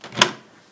door-open.mp3